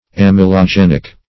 Search Result for " amylogenic" : The Collaborative International Dictionary of English v.0.48: Amylogenic \Am`y*lo*gen"ic\, a. 1.